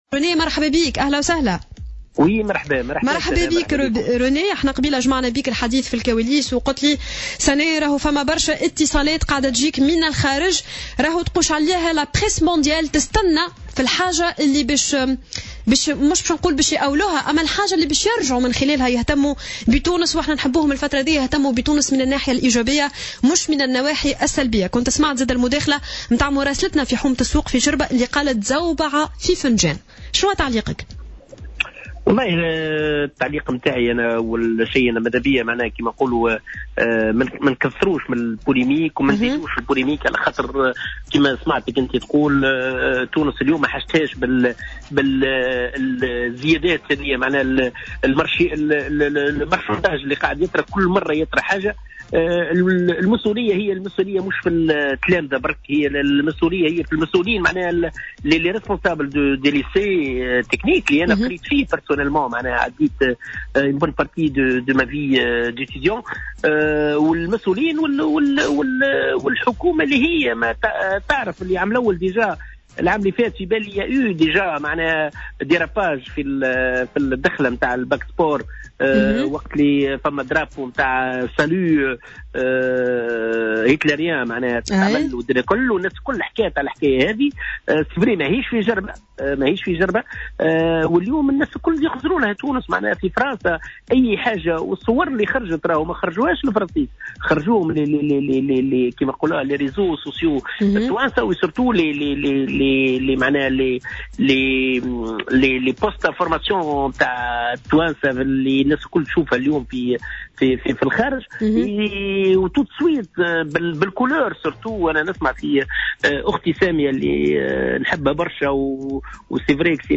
أكد رجل الأعمال وأحد المواطنين اليهود بتونس رينيه الطرابلسي في مداخلة له على الجوهرة "اف ام" اليوم الأربعاء في تعليق على الجدل الكبير الذي أثارته صورة دخلة الباك سبور بجربة حومة السوق أن المسؤولية الأكبر يتحملها الإطار التربوي والمسوؤلين في هذا المعهد.